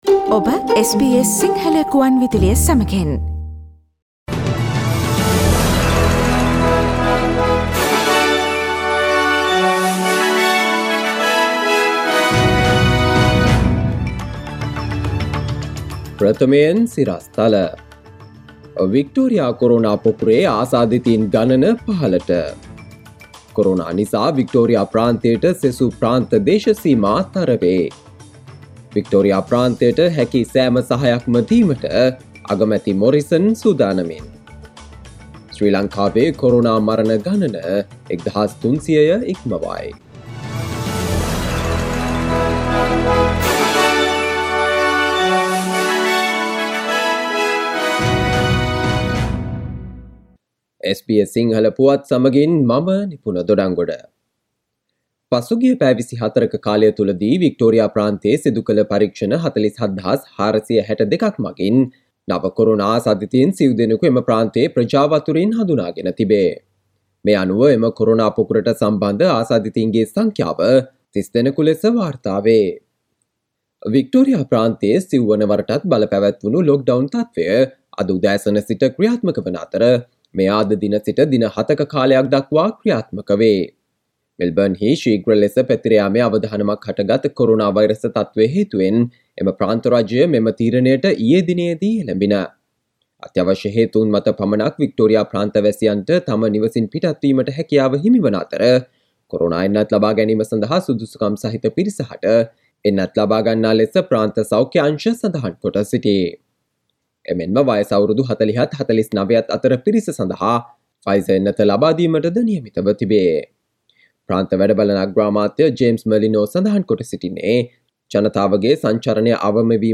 ඔස්ට්‍රේලියාවේ සහ ශ්‍රී ලංකාවේ පුවත් රැගත් SBS සිංහල ගුවන්විදුලියේ ප්‍රවෘත්ති ප්‍රකාශයට සවන් දෙන්න.